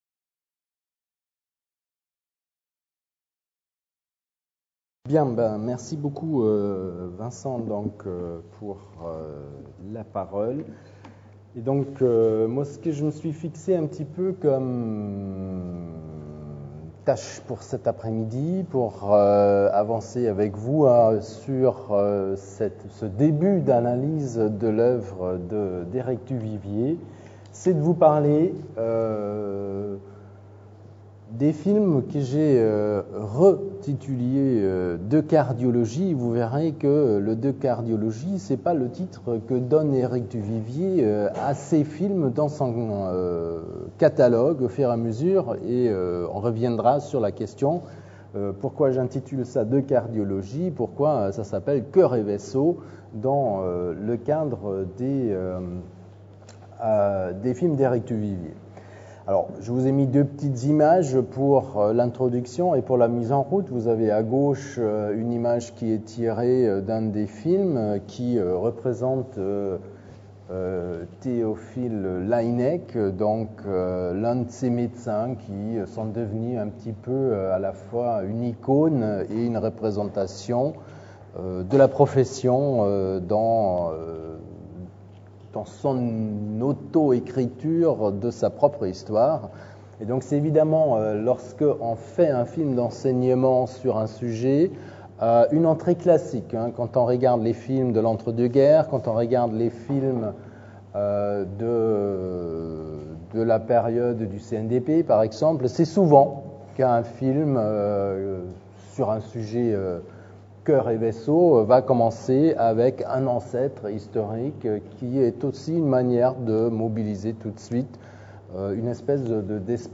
MEDFILM Journée d’étude n°2 21 septembre 2012 à l'Université Paris Diderot Grands Moulins